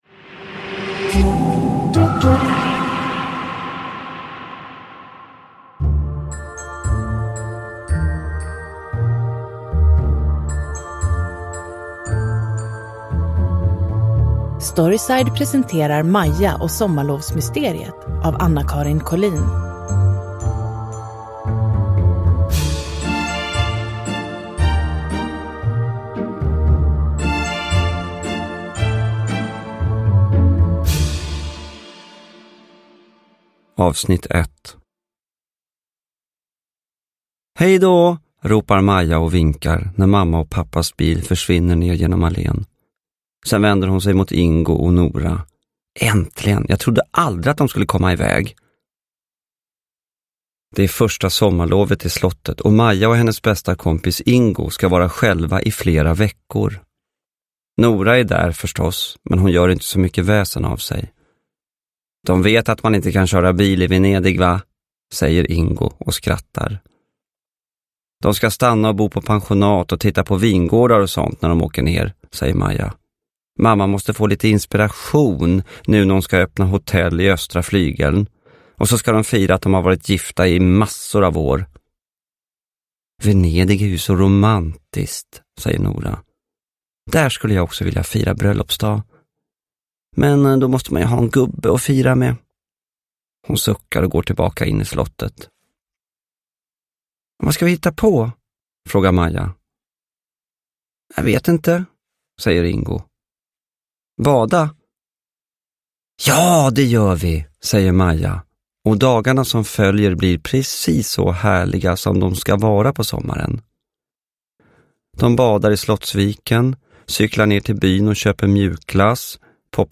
Maja och sommarlovsmysteriet – Ljudbok – Laddas ner
Uppläsare: Gustaf Hammarsten